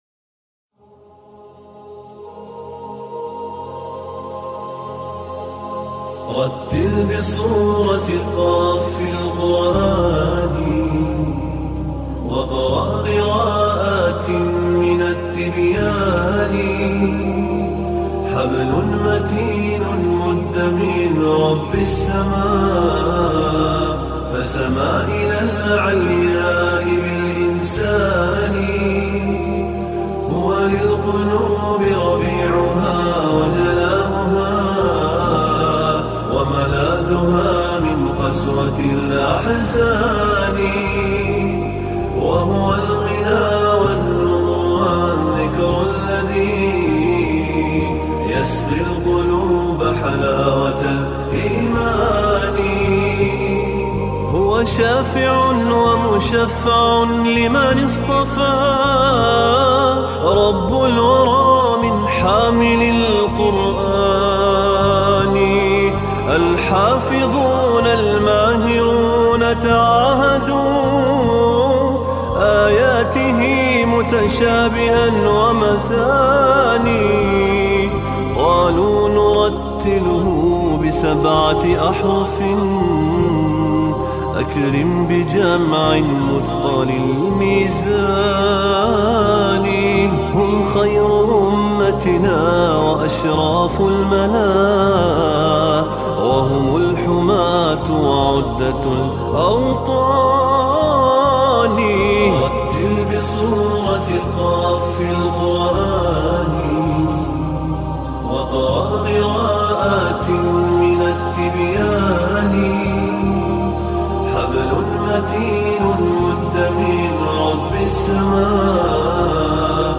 Нашид
Nashid.mp3